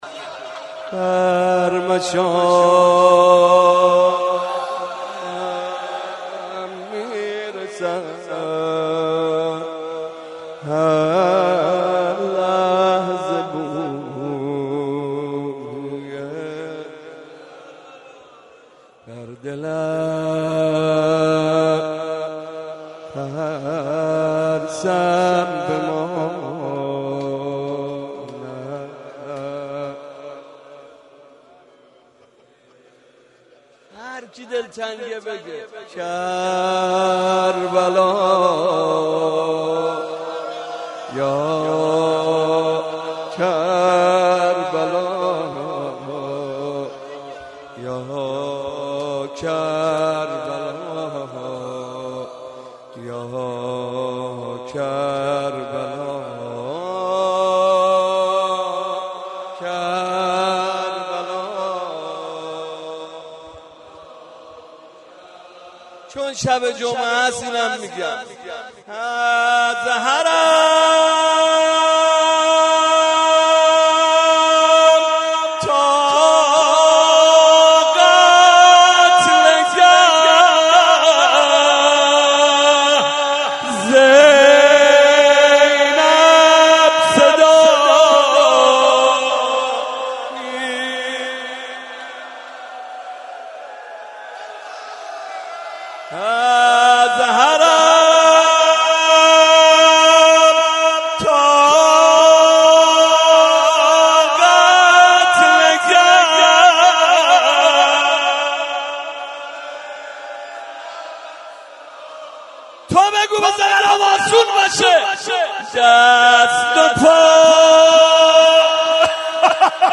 02.rozeh.mp3